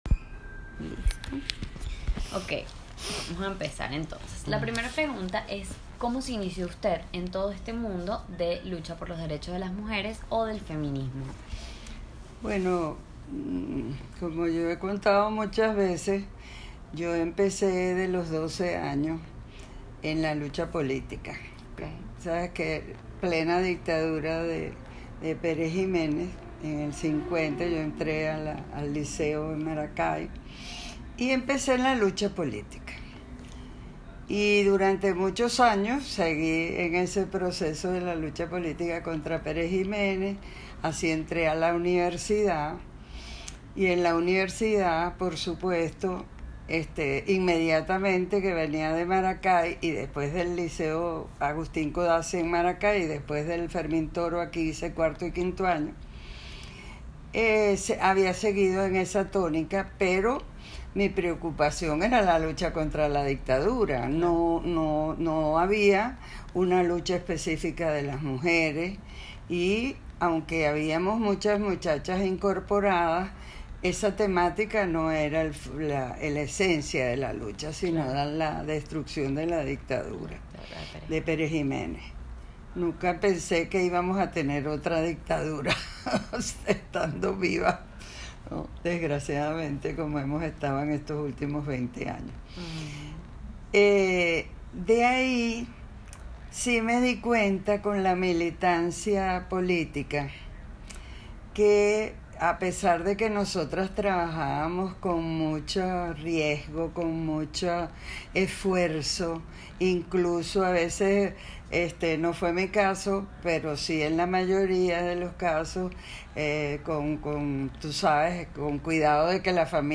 Escuchemos su testimonio de viva voz